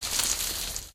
sounds / material / human / step / bush05gr.ogg